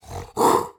Звуки лесного кота
Шипит